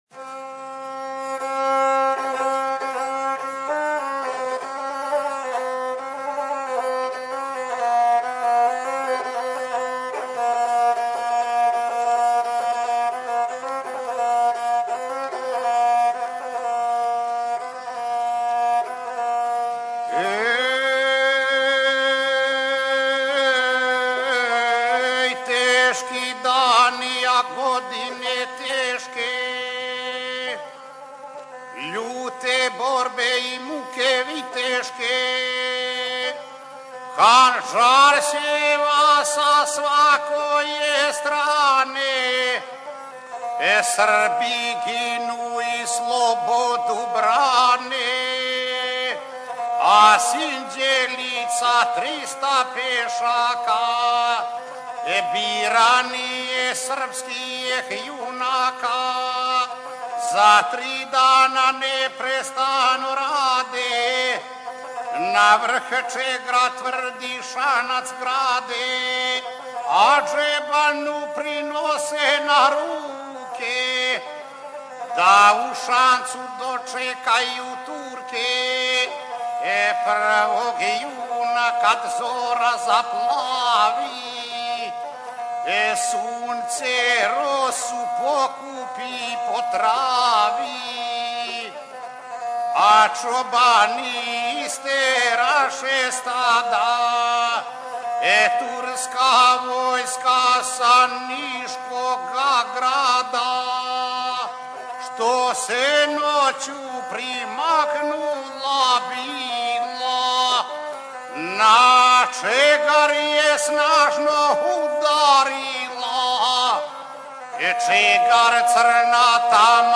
Tagged: Гусларске пјесме Извођач